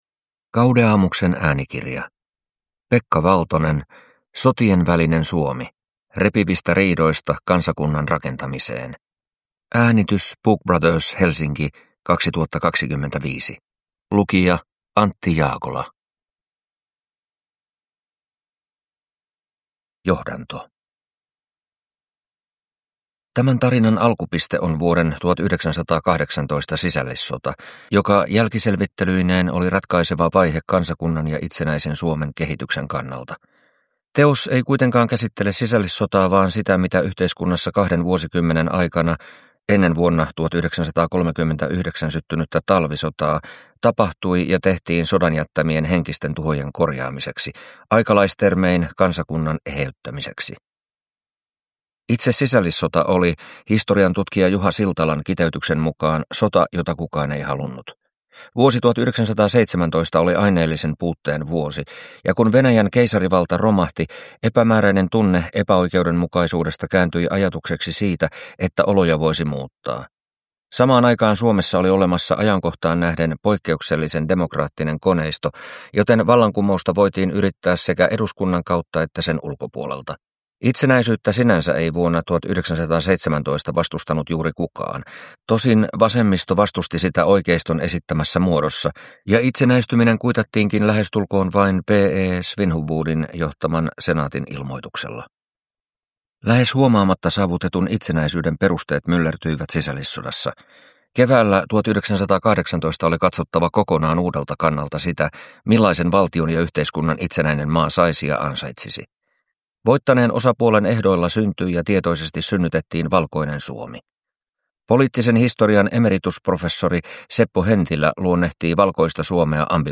Sotienvälinen Suomi – Ljudbok